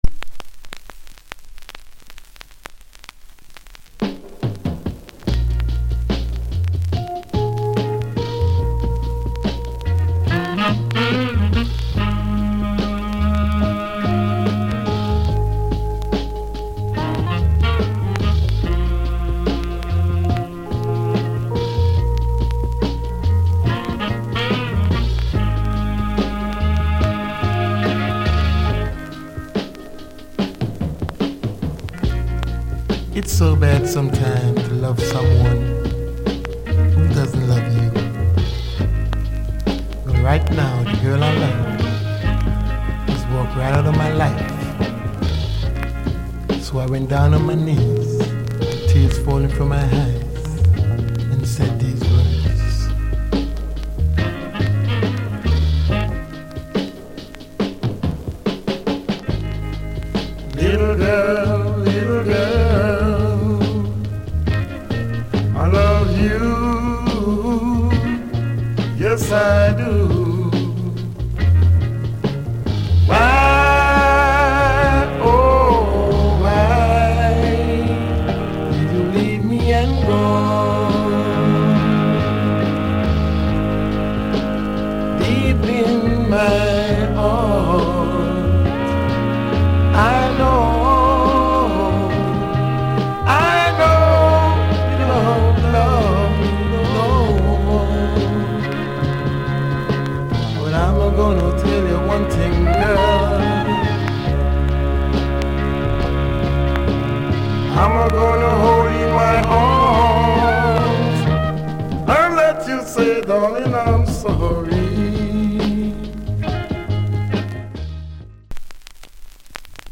* Soulful Vocal.